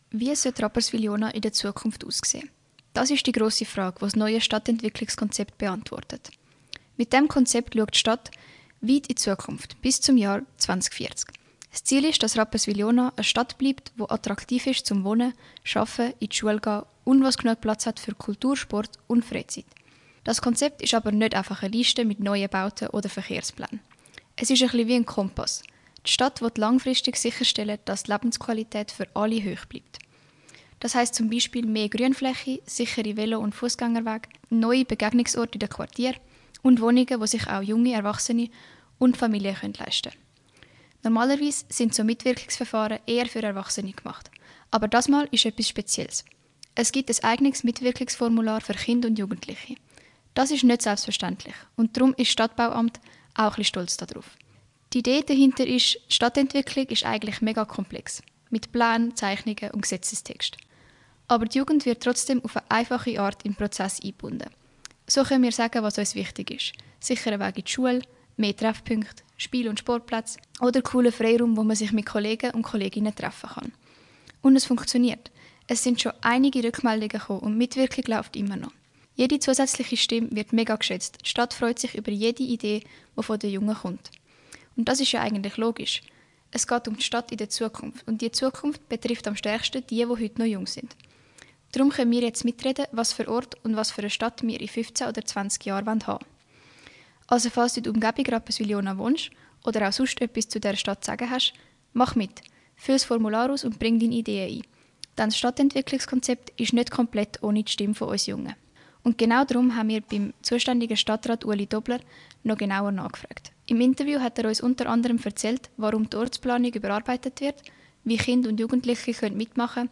Gespräch mit Stadtrat Ueli Dobler